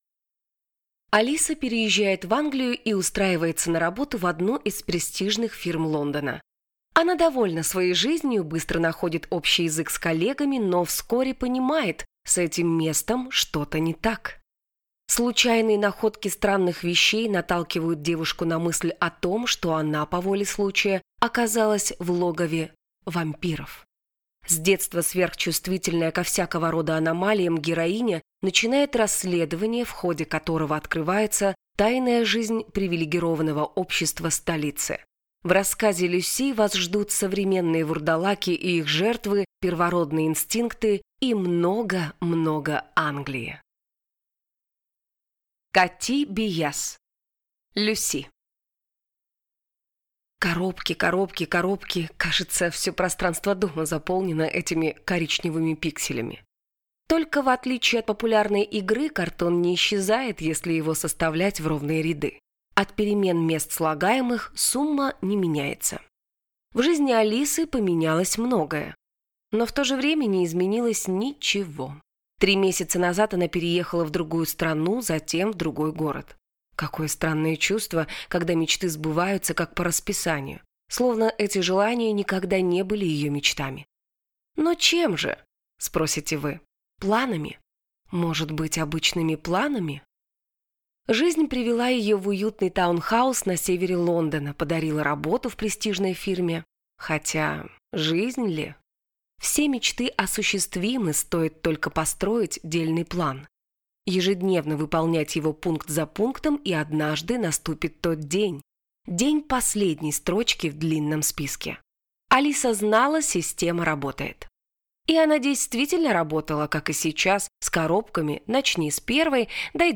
Аудиокнига Lucy | Библиотека аудиокниг